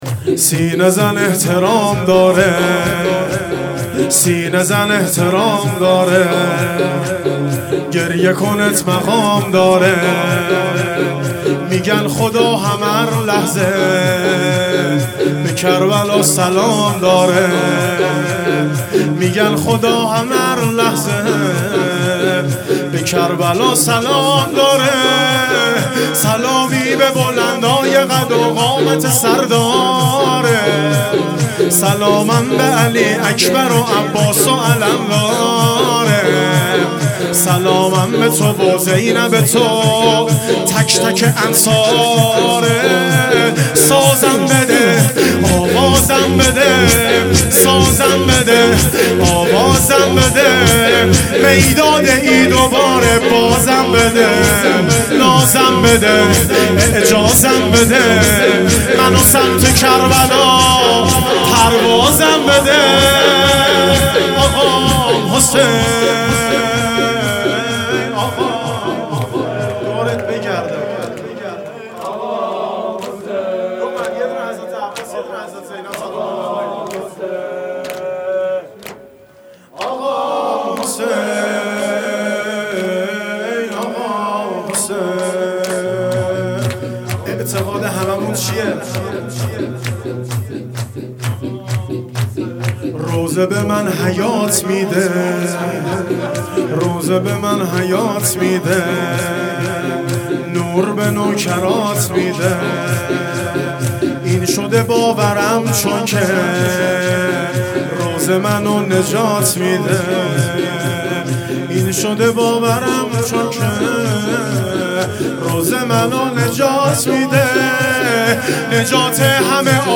شب دوم محرم 1400
شور